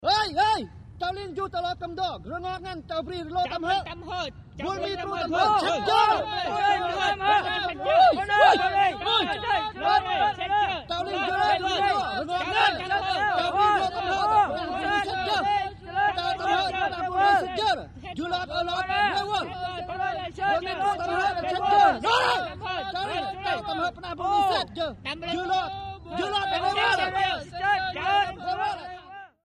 Troop Activity: ( Foreign ) Vietnamese Male Group Yells. Excited Or Upset.
Birds And Planes In Distant Big.